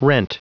Prononciation du mot rent en anglais (fichier audio)
Prononciation du mot : rent